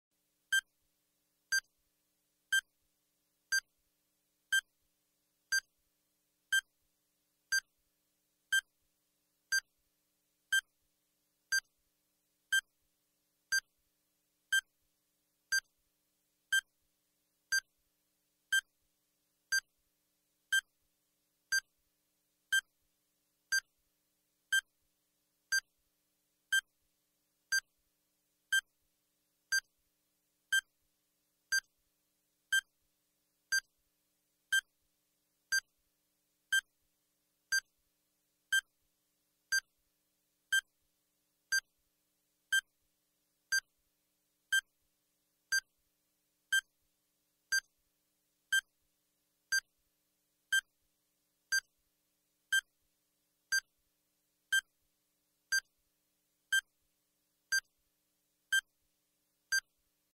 Звуки таймера бомбы